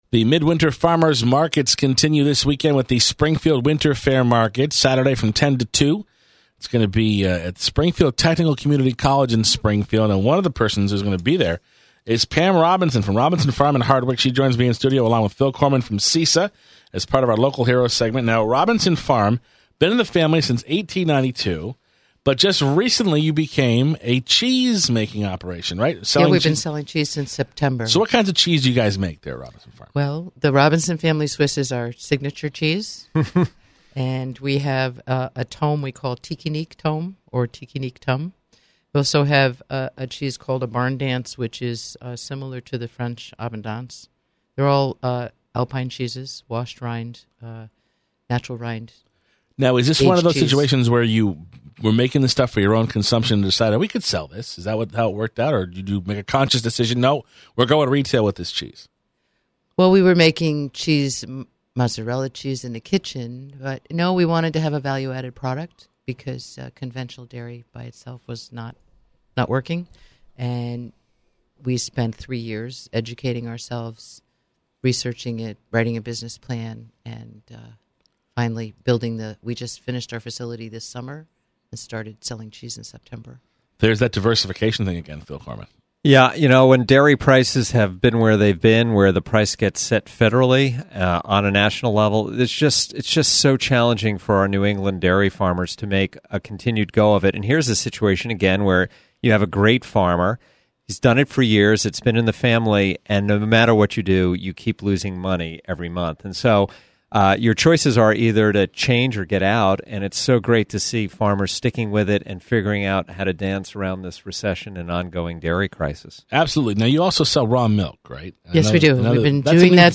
Interview: Robinson Farm